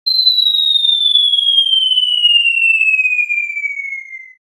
Tiếng Ném quả lụ đạn, quả bom Vèo….
Thể loại: Đánh nhau, vũ khí
Description: Tiếng ném lựu đạn, quả bom, tiếng bom rơi, âm thanh vèo, tiếng ném vật nổ” là hiệu ứng âm thanh sống động mô phỏng khoảnh khắc vật thể bay xé gió trước khi phát nổ.
tieng-nem-qua-lu-dan-qua-bom-veo-www_tiengdong_com.mp3